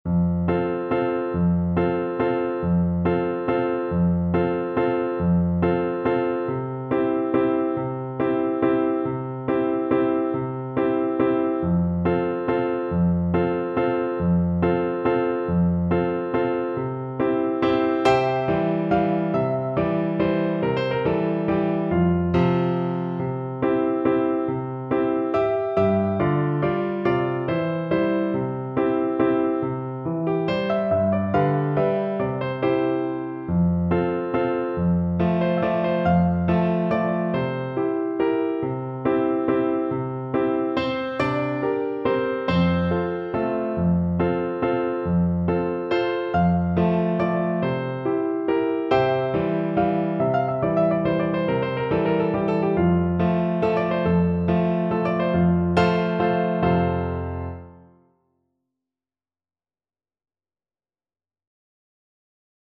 Free Sheet music for Soprano (Descant) Recorder
F major (Sounding Pitch) (View more F major Music for Recorder )
3/4 (View more 3/4 Music)
Allegretto = c.140
Traditional (View more Traditional Recorder Music)
Neapolitan Songs for Recorder